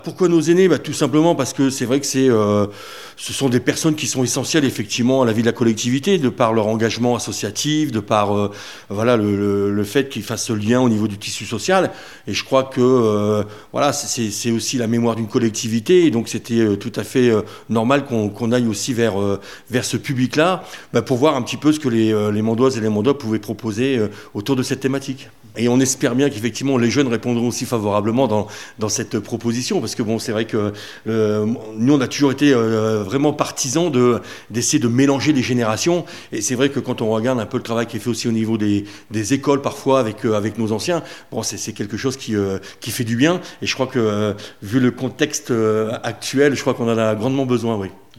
François Robin, premier adjoint à la Ville, explique ce choix.